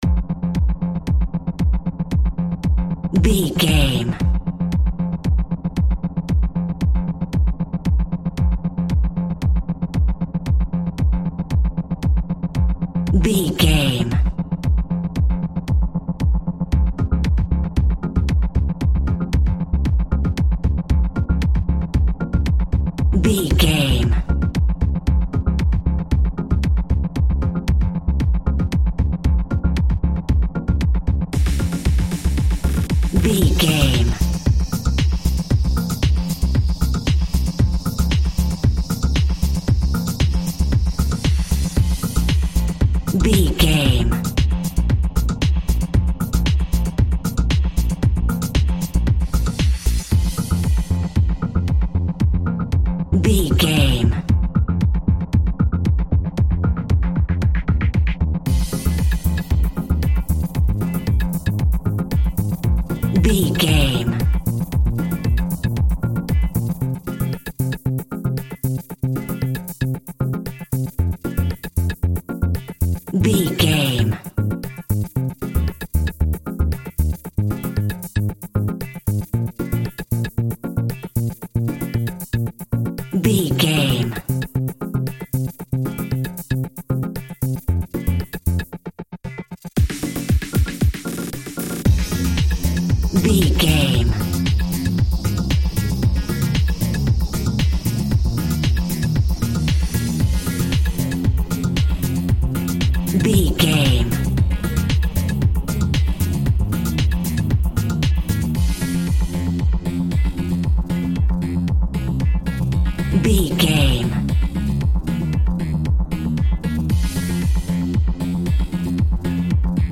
Aeolian/Minor
ethereal
dreamy
cheerful/happy
groovy
synthesiser
drums
drum machine
piano
house
electro dance
techno
trance
synth leads
synth bass
upbeat